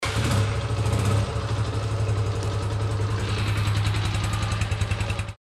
Escucha el sonido de su motor aquí:
Audio del motor GL125
Cilindrada124.8 cc